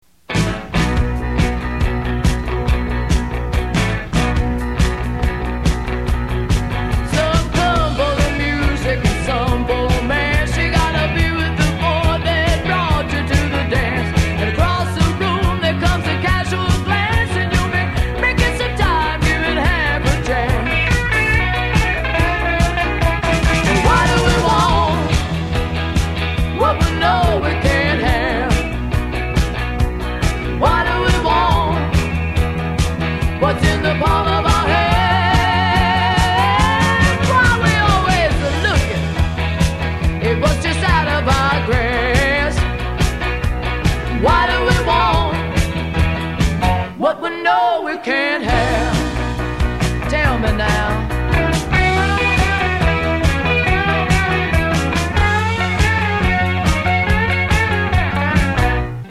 Country Stuff